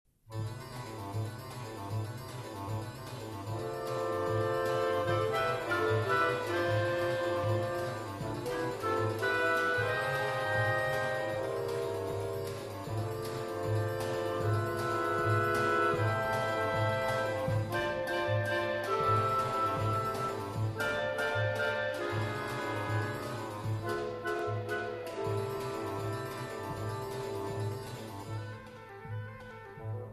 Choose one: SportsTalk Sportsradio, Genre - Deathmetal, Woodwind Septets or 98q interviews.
Woodwind Septets